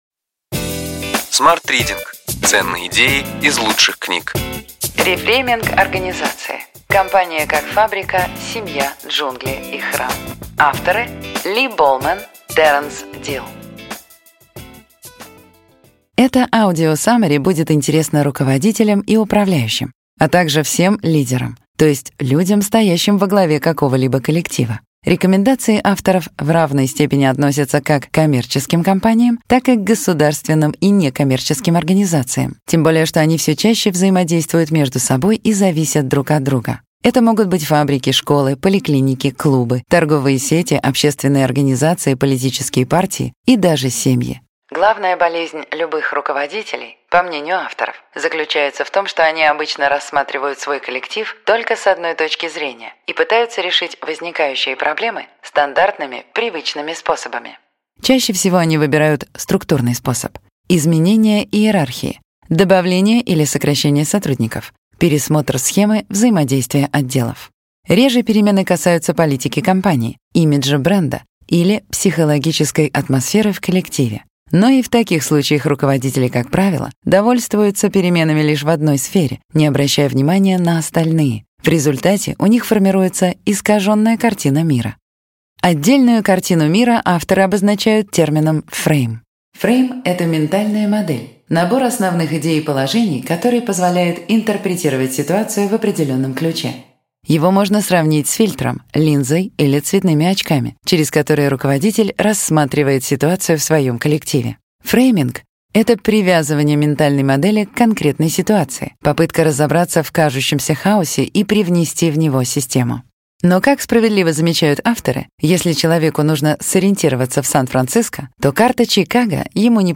Аудиокнига Ключевые идеи книги: Рефрейминг организации. Компания как фабрика, семья, джунгли и храм.